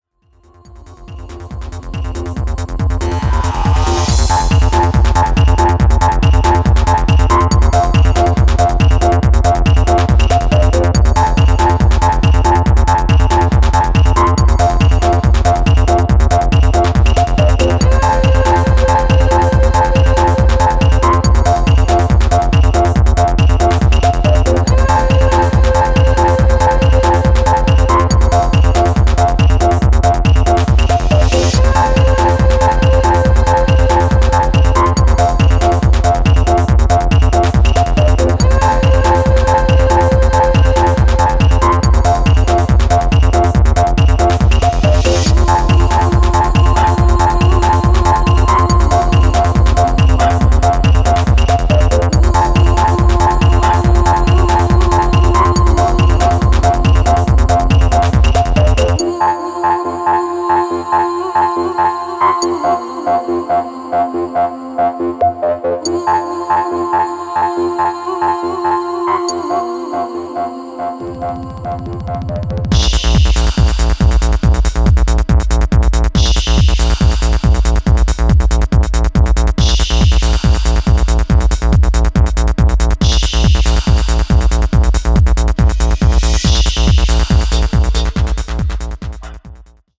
audio remasterizado